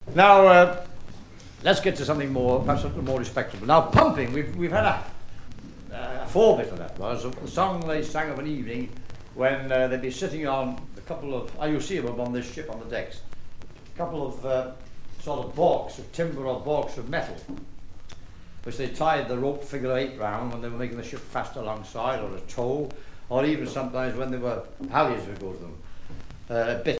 explication sur des chansons maritimes
Catégorie Témoignage